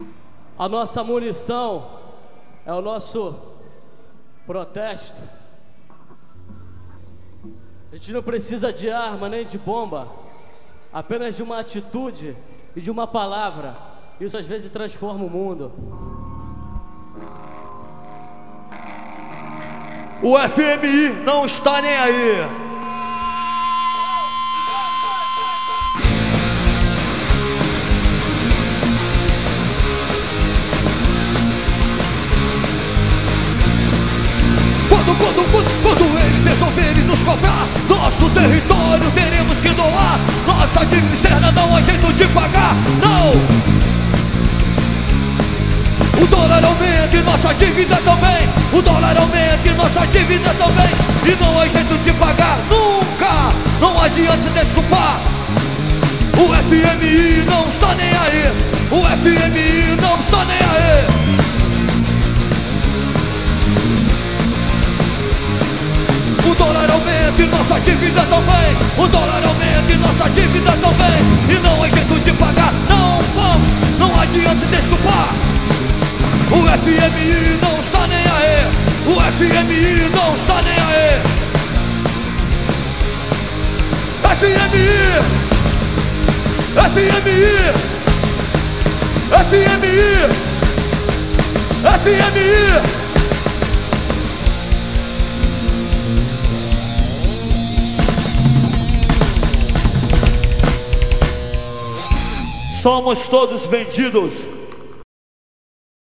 COVER.